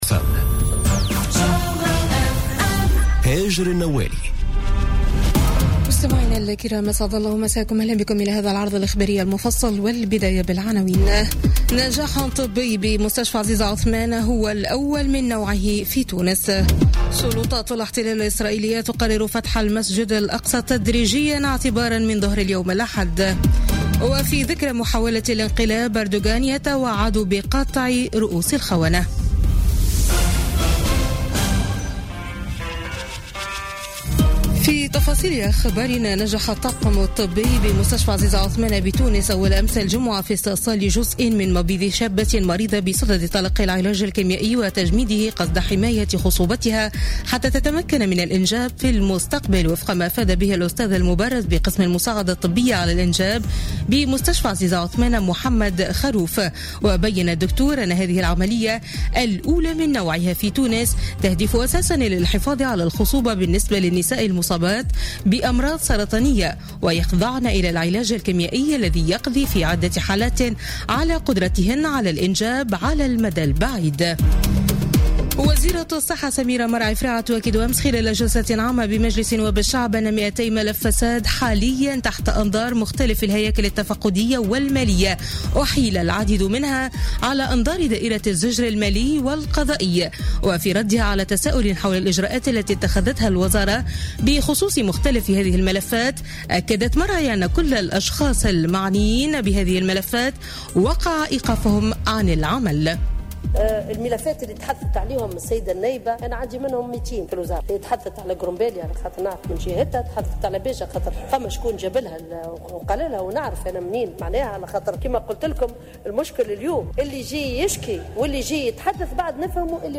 نشرة أخبار منتصف الليل ليوم الأحد 16 جويلية 2017